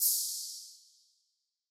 Open Hats
drill oh.wav